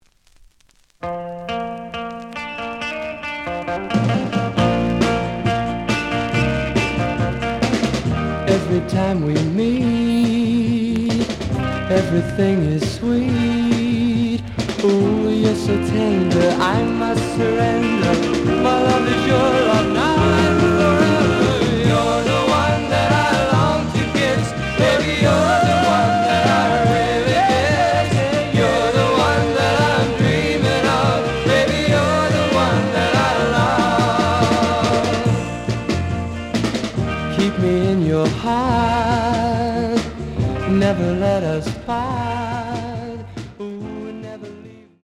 The audio sample is recorded from the actual item.
●Format: 7 inch
●Genre: Rock / Pop
Slight edge warp.